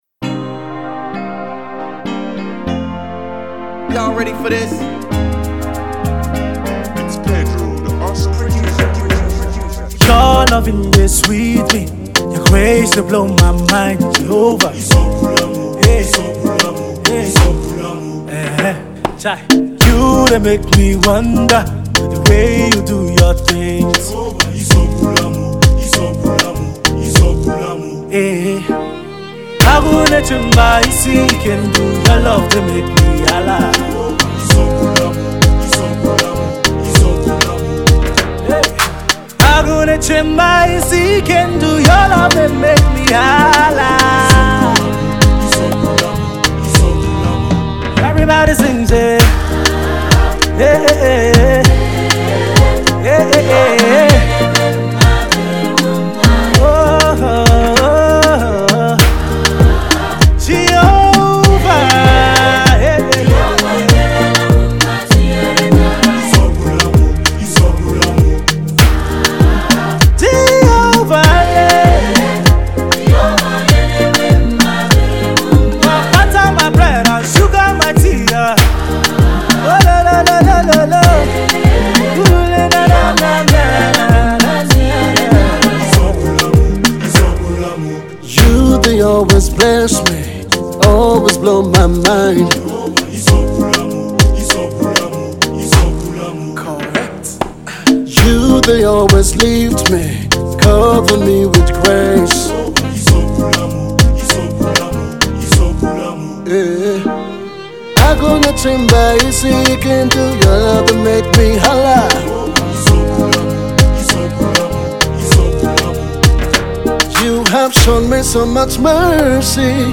celebration song